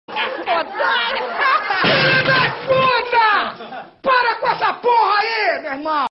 away para com essa poha Meme Sound Effect
The away para com essa poha meme sound effect is widely used in Instagram Reels, YouTube Shorts, gaming videos, and funny meme edits.